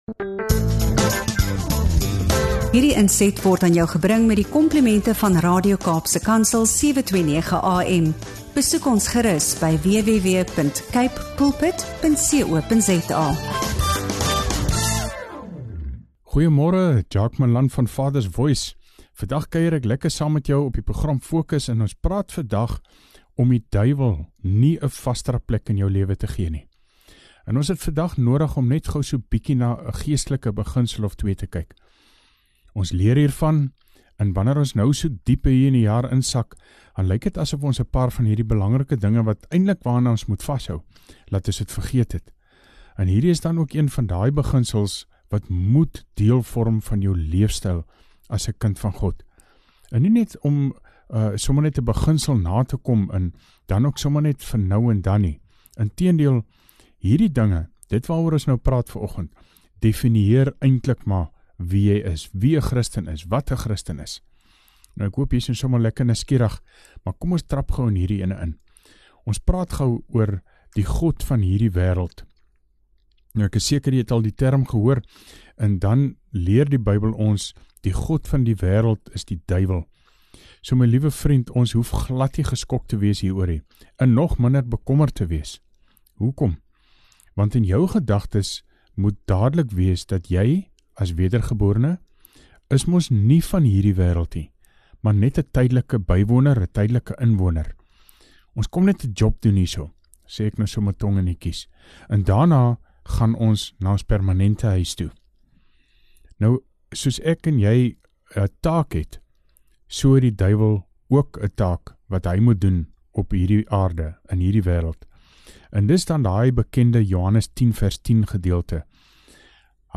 ’n Bemoedigende en uitdagende gesprek vir elke Christen wat geestelik wil groei en standvastig wil leef in God se waarheid.